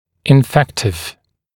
[ɪn’fektɪv][ин’фэктив]инфекционный, заразный